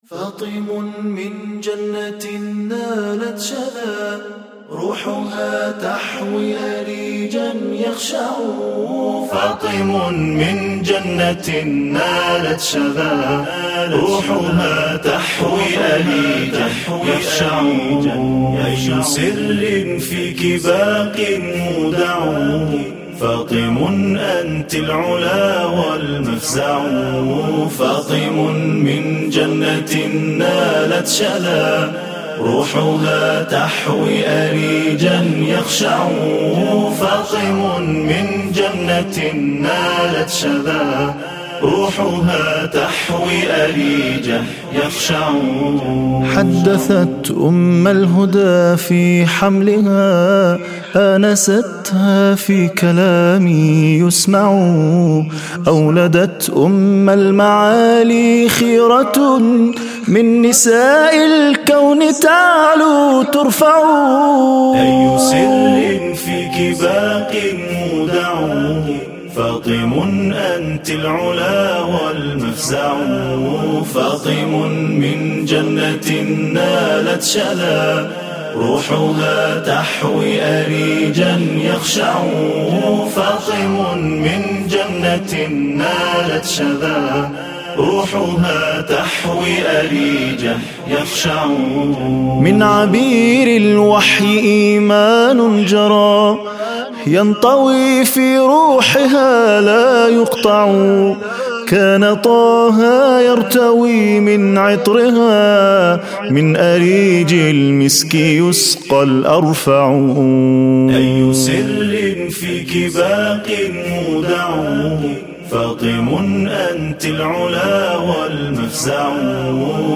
أنشودة أي سر - ولادة الصديقة الطاهرة الزهراء (ع)